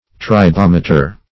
Search Result for " tribometer" : The Collaborative International Dictionary of English v.0.48: Tribometer \Tri*bom"e*ter\, n. [Gr. tri`bein to rub + -meter: cf. F. tribom[`e]tre.]